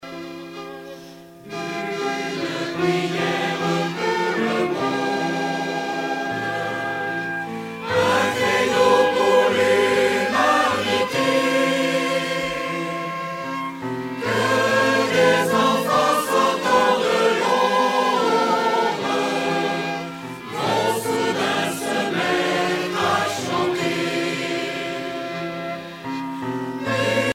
Genre strophique